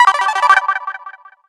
new_achievement.wav